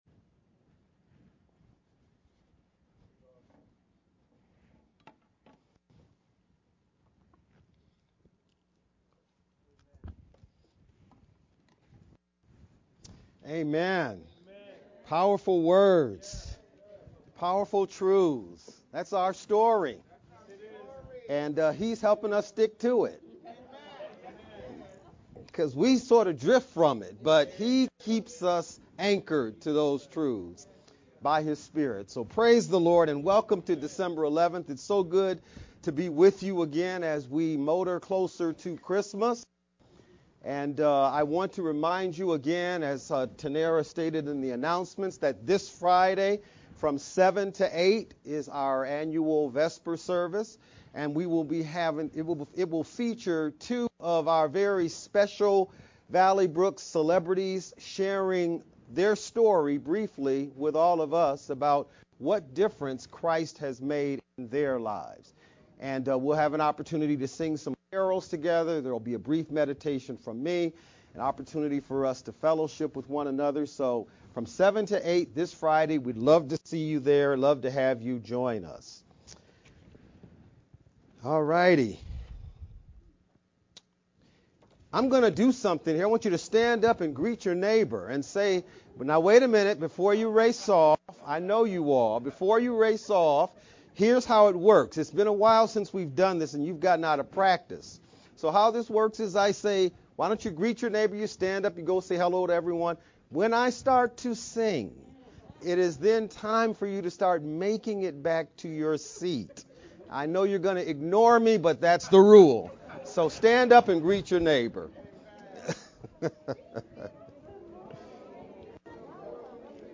Dec.-11th-VBCC-Sermon-edited-sermon-only-CD.mp3